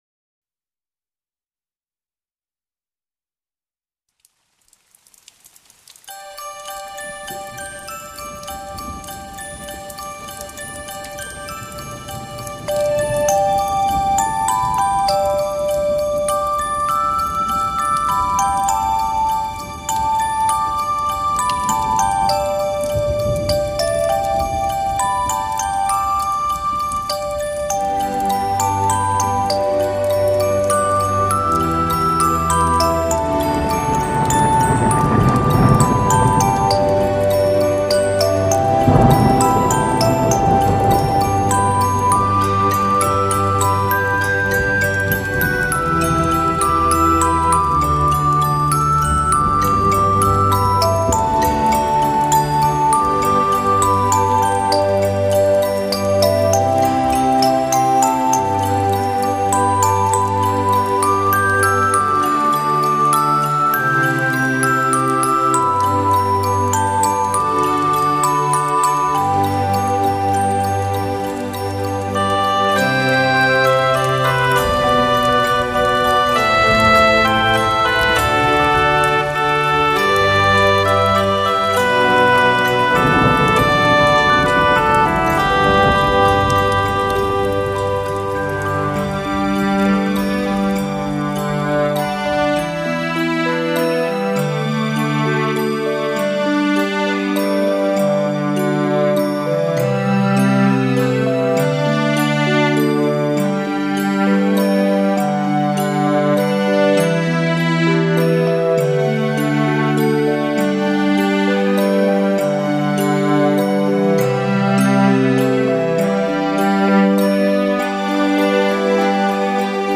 悠柔婉约的旋律像轻风阵阵，晶脆清凉的音符如细雨丝丝，为燥热烦闷的褥夏带来了舒适和凉意。
晶脆清亮的音符纷纷坠下，彷佛褥夏从天而降的细雨，丝丝凉意销融心间的烦闷难耐…；
轻柔婉约的旋律缓缓流泄，穿过发丝、飘过耳际，如夏夜微风轻拂般的舒适惬意，令人微醉…